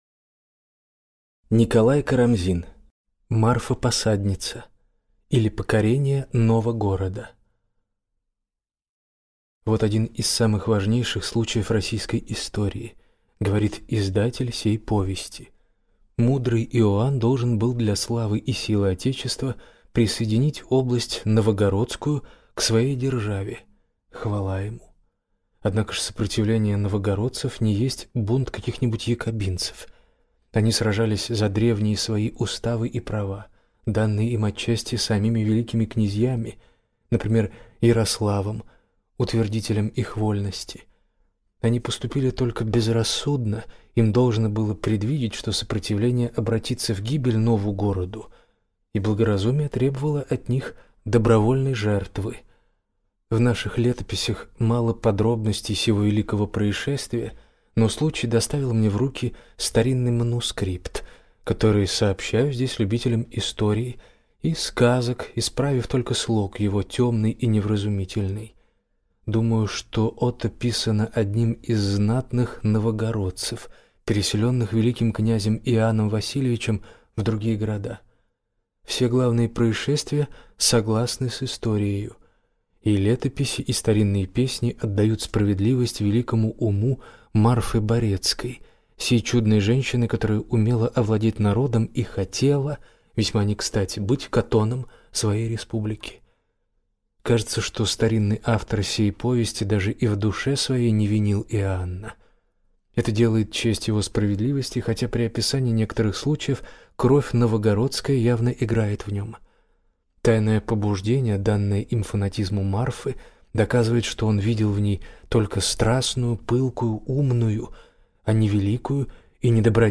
Аудиокнига Марфа-Посадница, или Покорение Новагорода | Библиотека аудиокниг